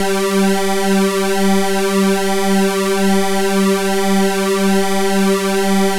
G3_jx_phat_lead_1.wav